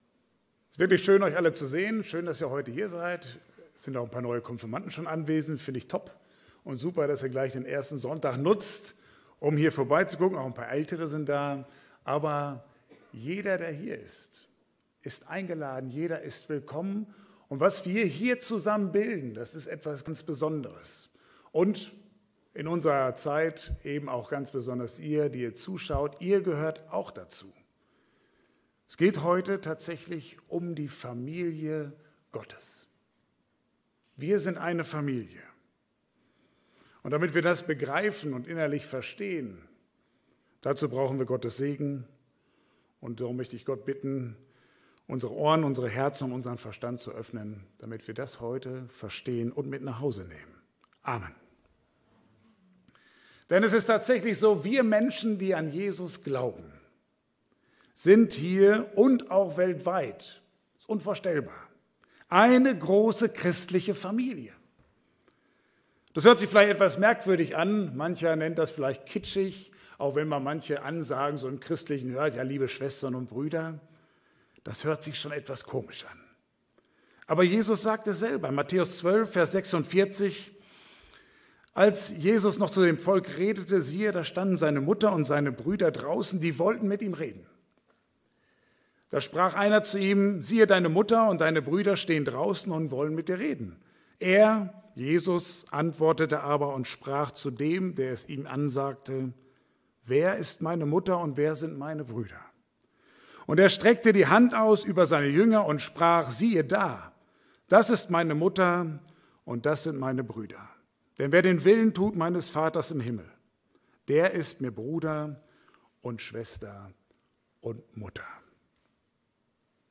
Passage: Apostelgeschichte 6,1-7 Dienstart: Gottesdienst « Der Grund ist gelegt